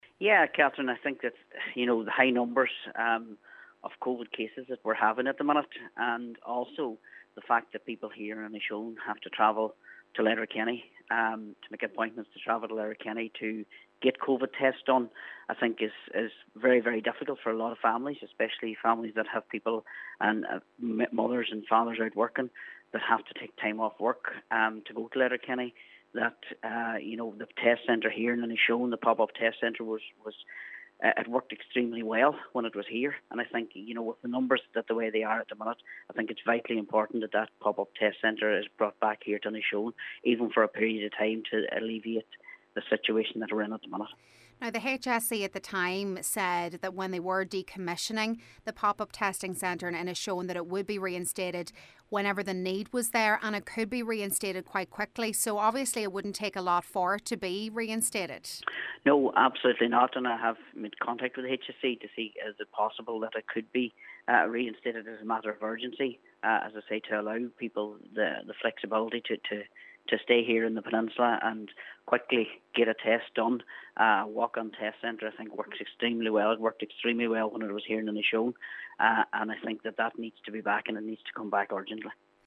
Local Cllr Martin McDermott says in a lot of cases this simply isnt feasible and the facility must be reopened: